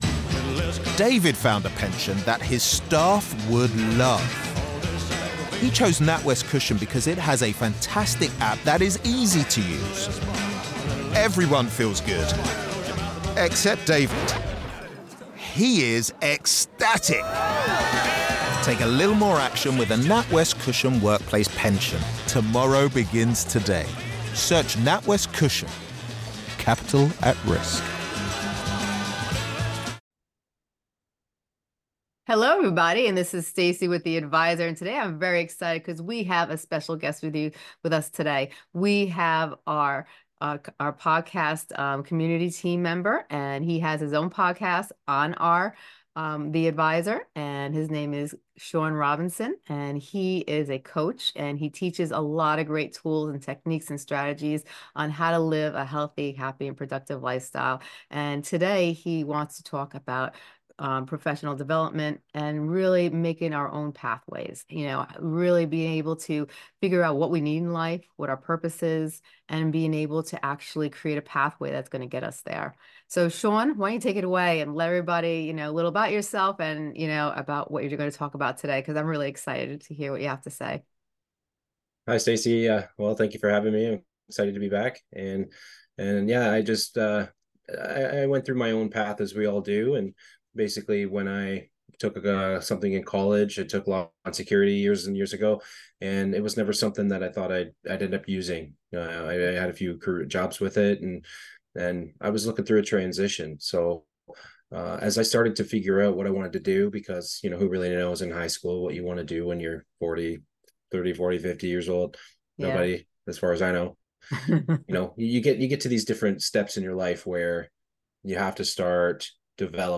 Share Facebook X Subscribe Next Exclusive Interview